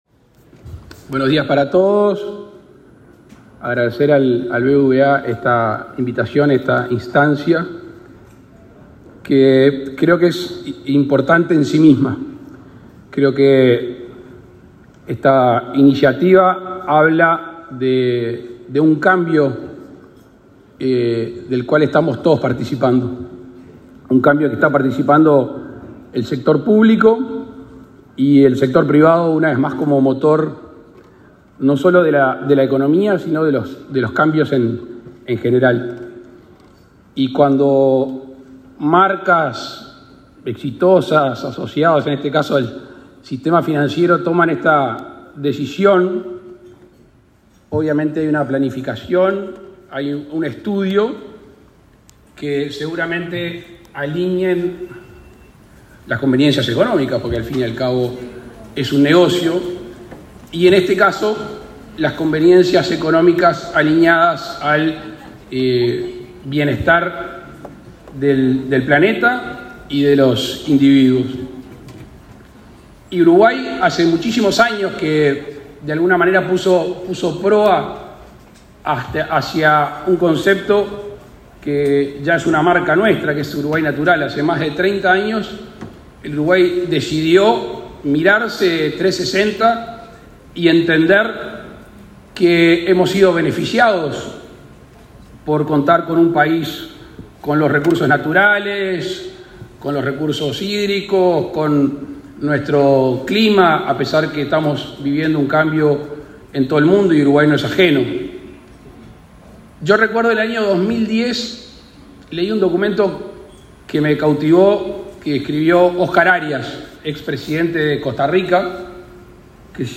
Palabras del presidente Luis Lacalle Pou
El presidente de la República, Luis Lacalle Pou, participó este martes 18 en un foro de sostenibilidad, organizado por el BBVA.